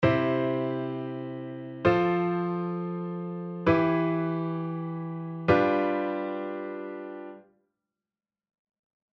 ↓の音源のような、キー＝CでC⇒F⇒Em⇒G7があるとします。